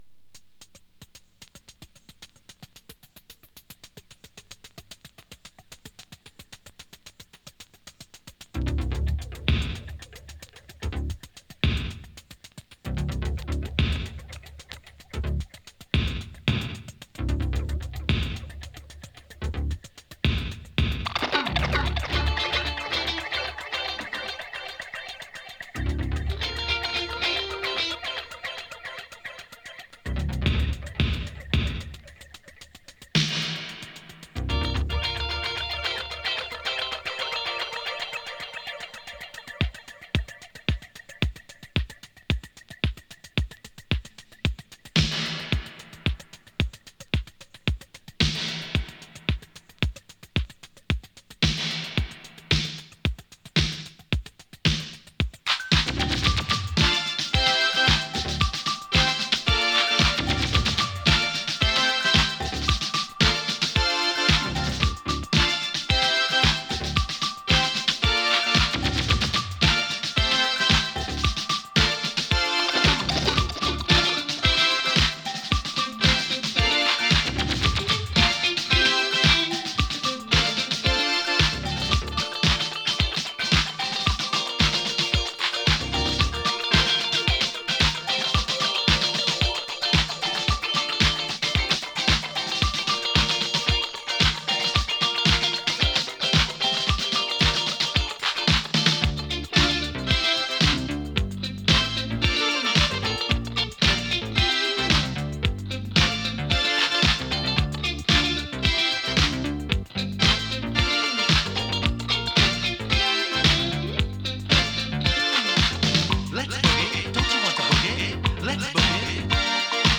エレクトロなオールドスクール・テイストのディスコ・ブギー！